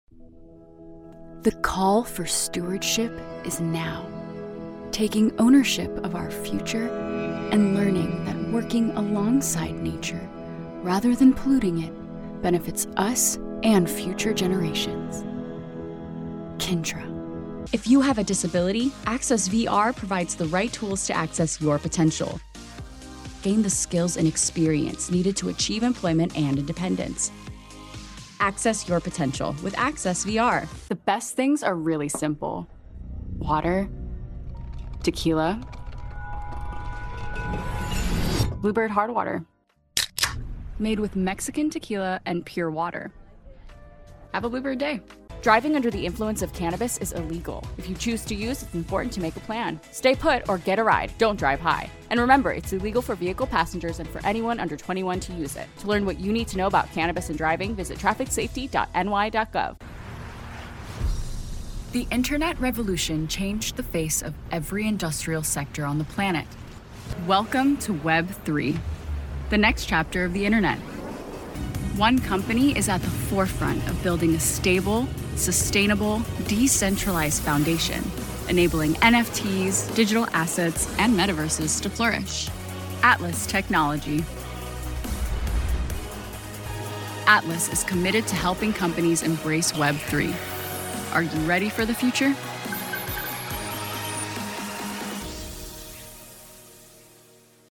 Female Voice Over, Dan Wachs Talent Agency.
Vivacious, Enthusiastic, Bilingual
Showcase